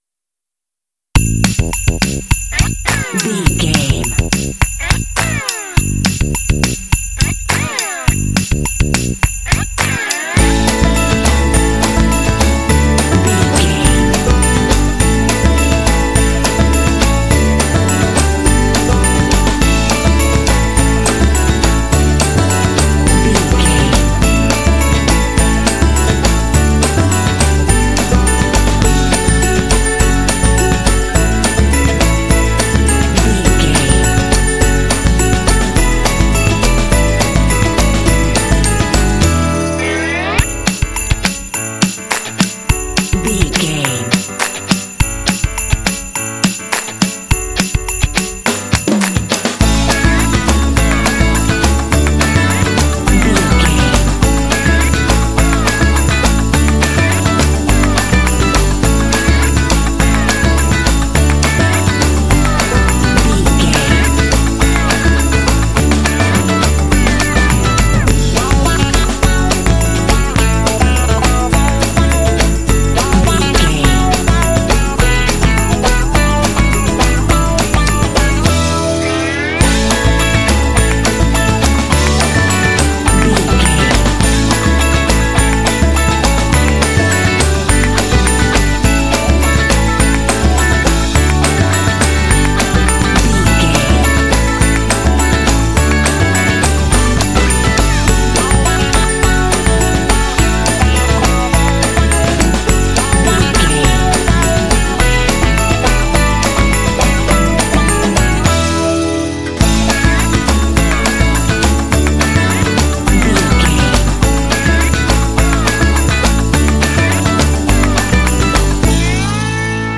Ionian/Major
Fast
festive
joyful
energetic
bass guitar
electric guitar
sleigh bells
drums
acoustic guitar
pop
rock